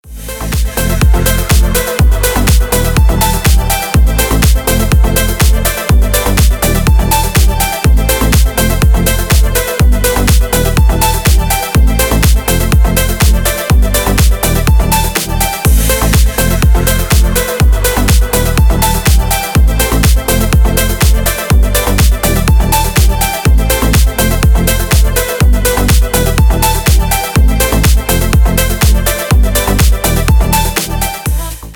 Dance рингтоны
танцевальный трек на рингтон